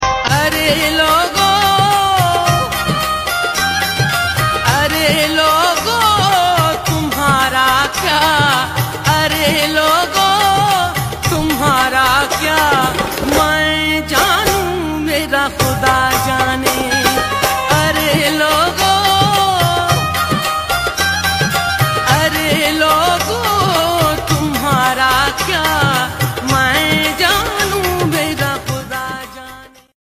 sufyana kalam